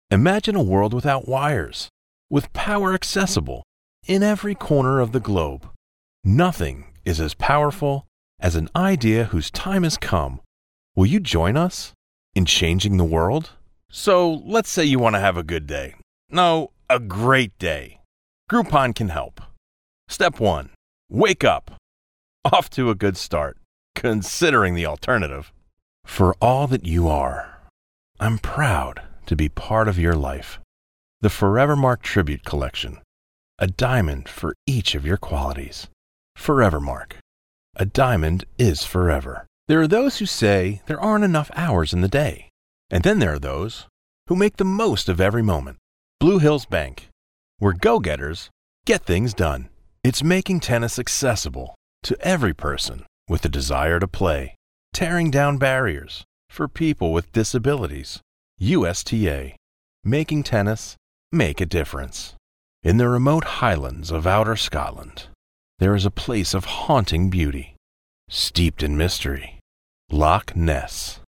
Male
English (North American)
Adult (30-50), Older Sound (50+)
Dry Demo